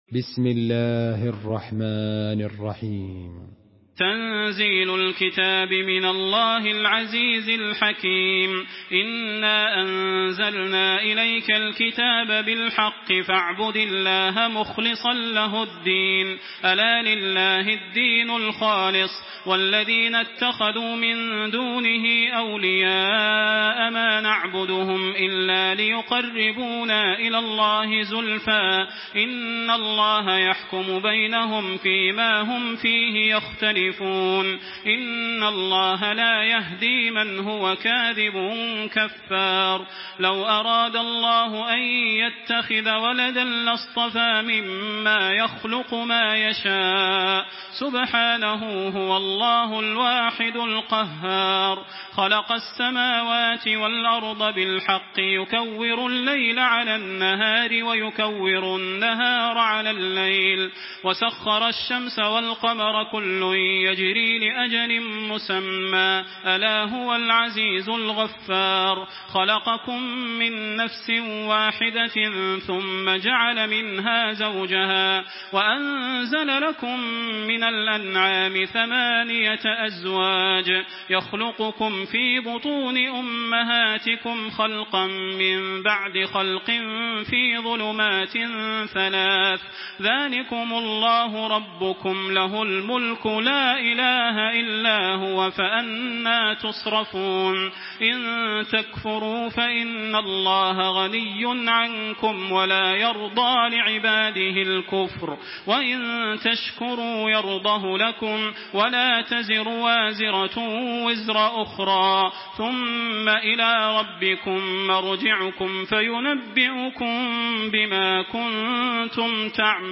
Surah Az-zumar MP3 in the Voice of Makkah Taraweeh 1426 in Hafs Narration
Murattal Hafs An Asim